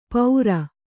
♪ paura